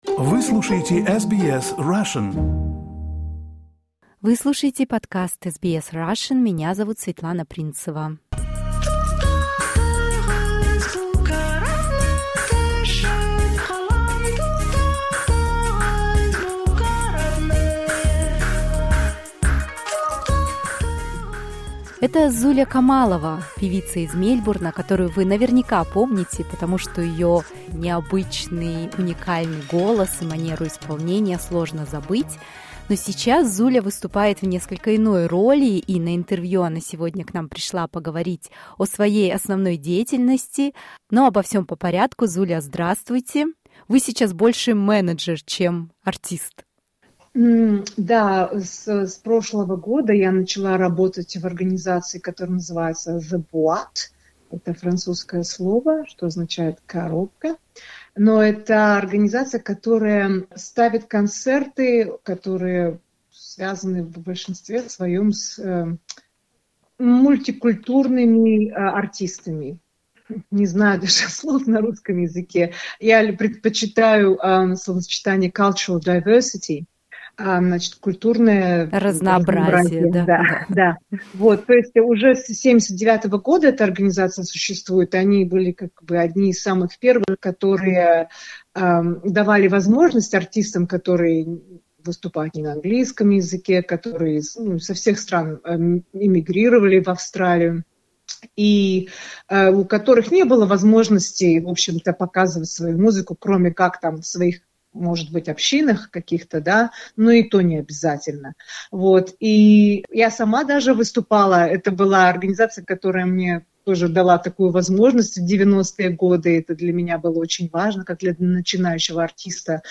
Другие интервью